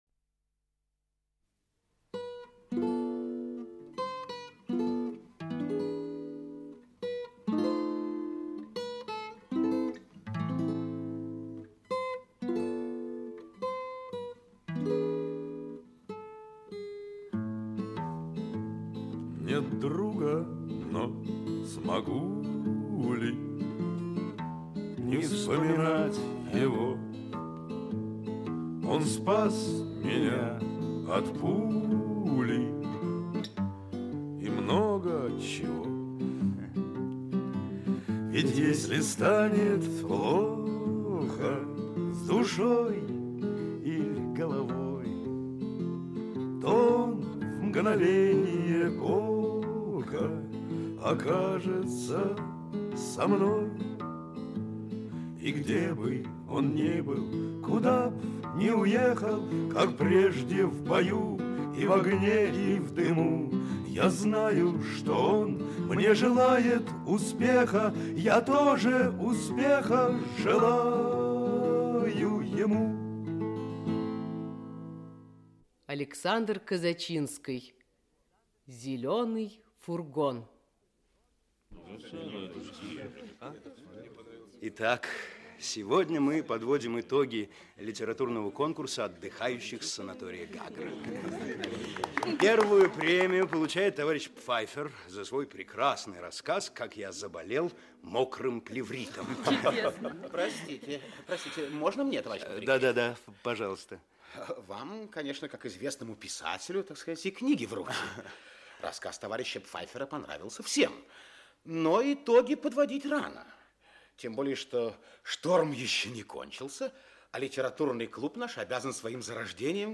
Aудиокнига Зеленый фургон Автор Александр Козачинский Читает аудиокнигу Владимир Высоцкий.